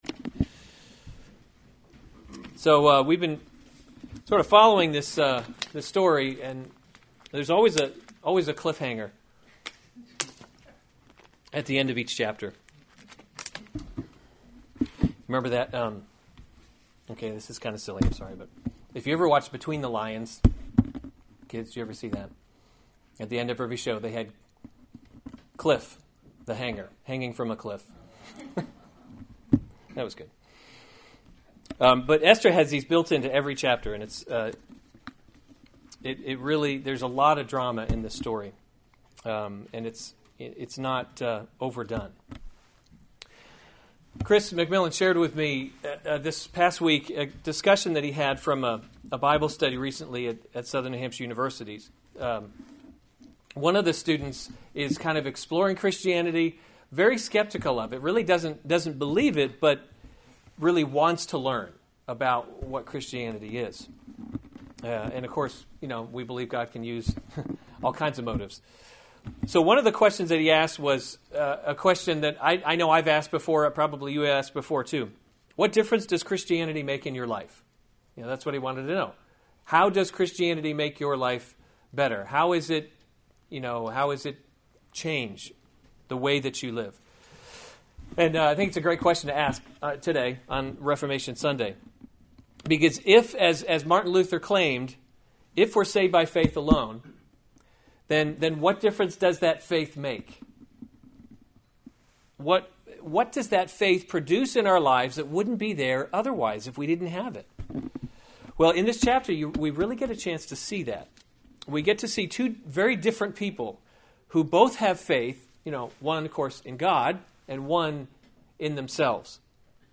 October 29, 2016 Esther: God’s Invisible Hand series Weekly Sunday Service Save/Download this sermon Esther 5:1-14 Other sermons from Esther Esther Prepares a Banquet 5:1 On the third day Esther put […]